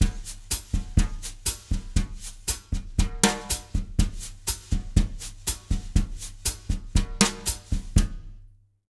120BOSSA02-L.wav